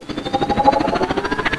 chopperCharge.ogg